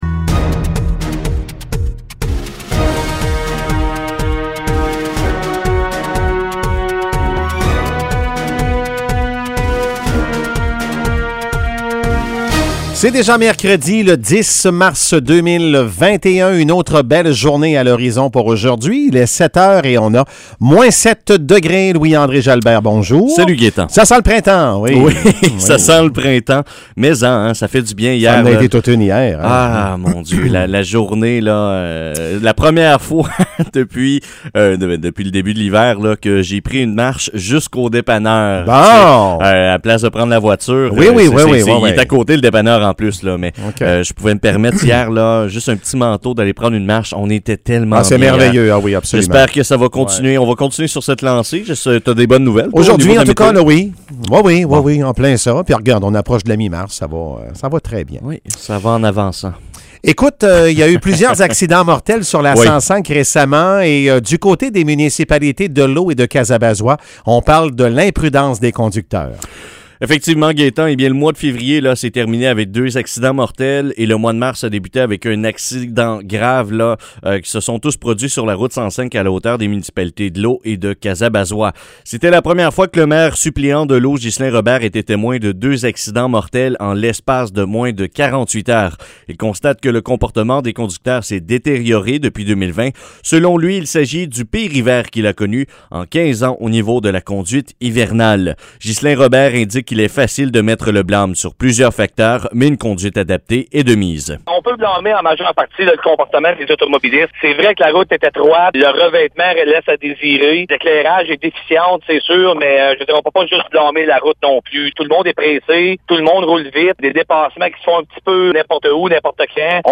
Nouvelles locales - 10 mars 2021 - 7 h